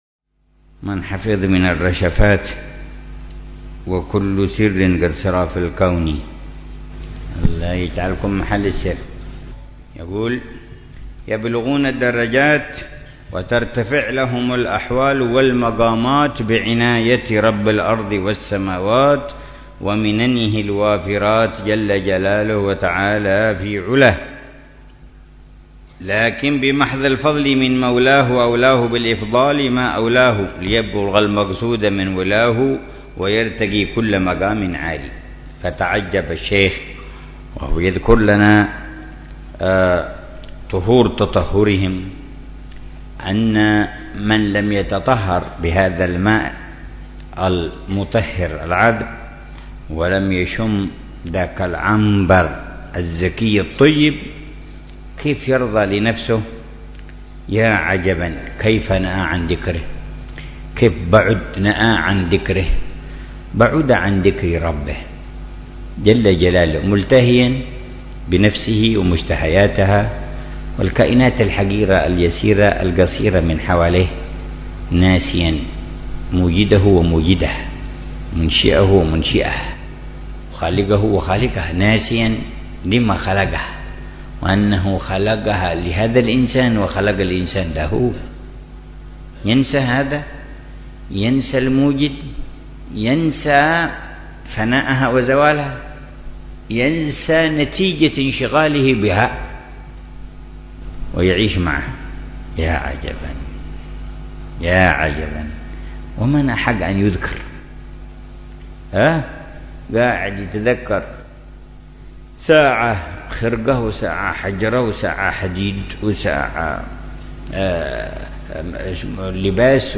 رشفات أهل الكمال ونسمات أهل الوصال - الدرس السادس والخمسون
شرح الحبيب عمر بن محمد بن حفيظ لرشفات أهل الكمال ونسمات أهل الوصال.